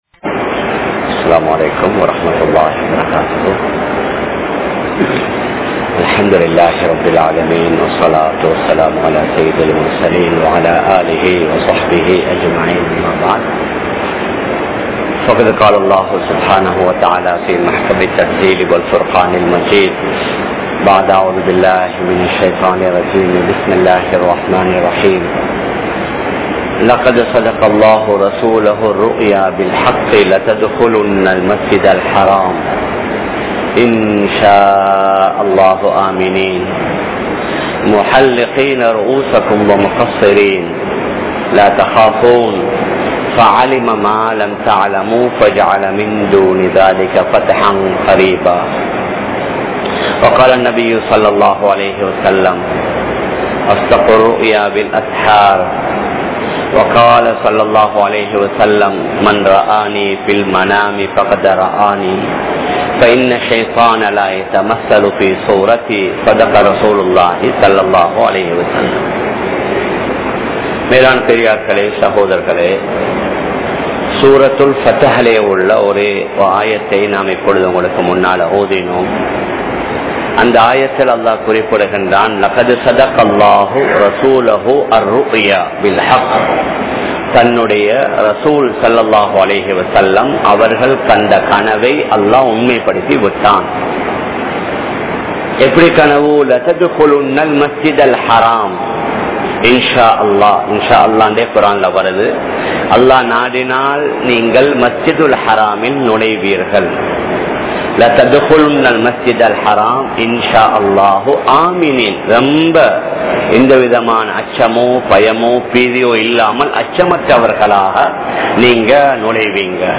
Kanavukkaana Vilakkangal (கணவுக்கான விளக்கங்கள்) | Audio Bayans | All Ceylon Muslim Youth Community | Addalaichenai